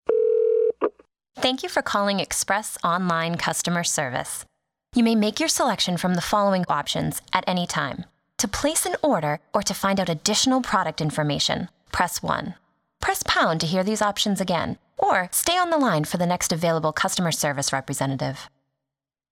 Voice Over
Express * Voice Mail